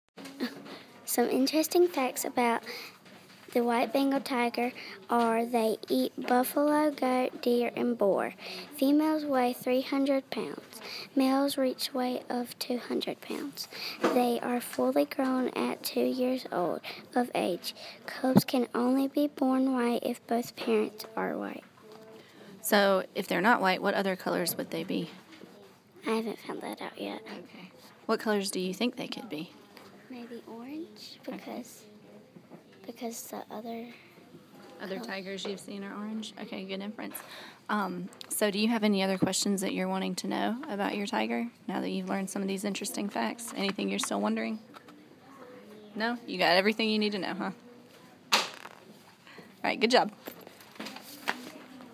White Bengal Tiger